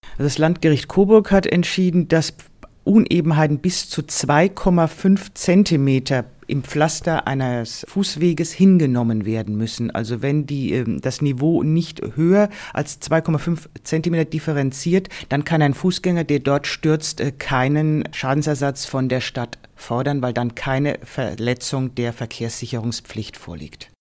O-Ton + Magazin: Stolperfallen auf Gehwegen bis zu 2,5 Zentimetern
O-Töne / Radiobeiträge, Ratgeber, Recht, , , , , , ,